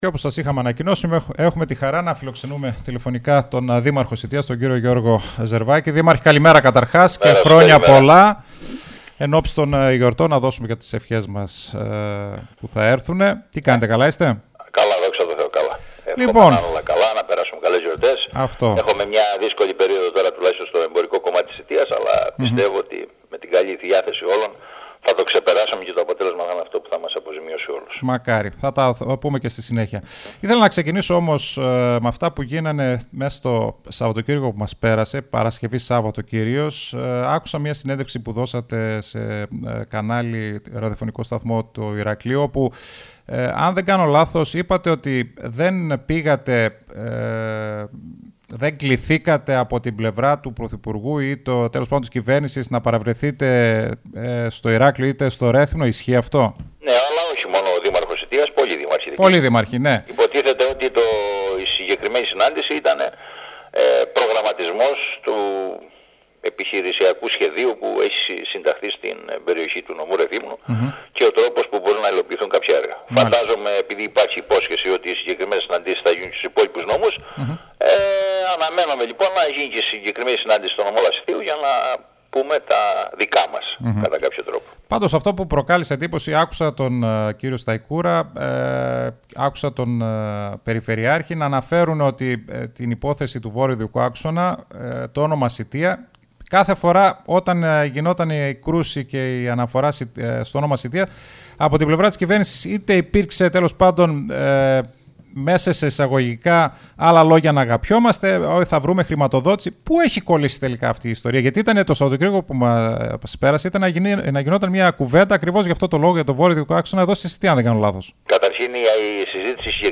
O Δήμαρχος Σητείας εφ΄ολης της ύλης στον STYLE 100(ηχητικό)